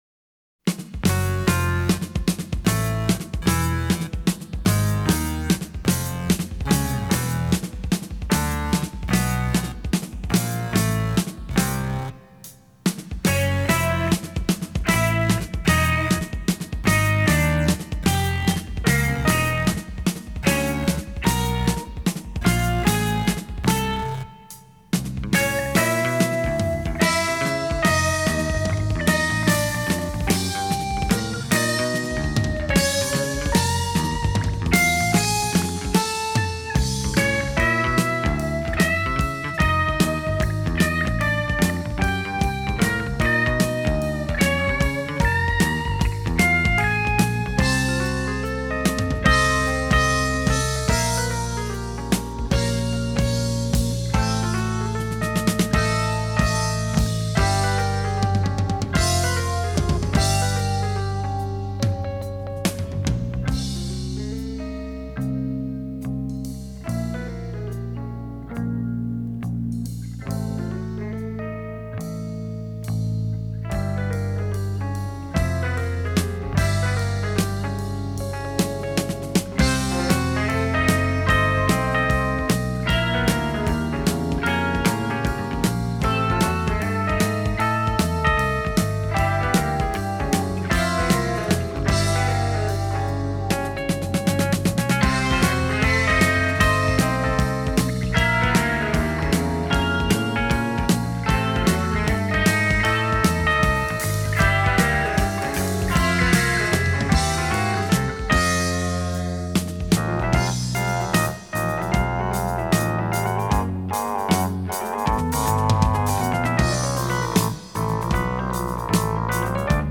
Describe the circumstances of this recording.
recorded in Brussels